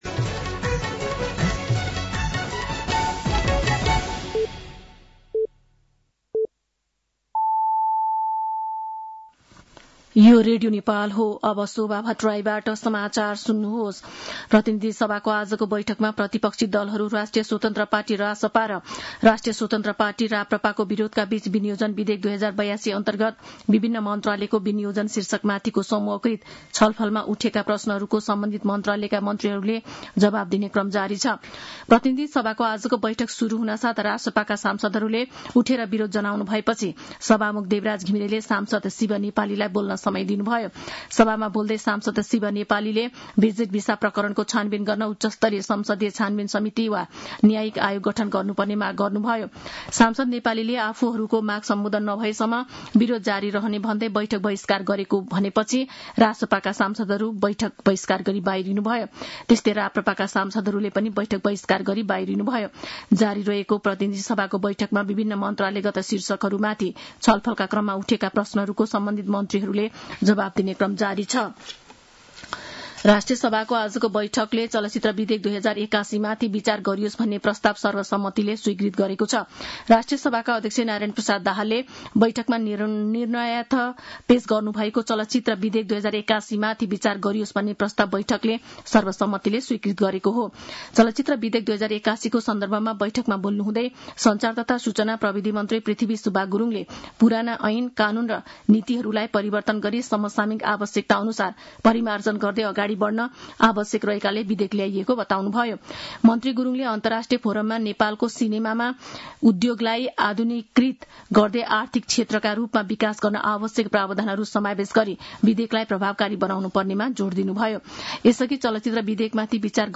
साँझ ५ बजेको नेपाली समाचार : ९ असार , २०८२
5.-pm-nepali-news-1-6.mp3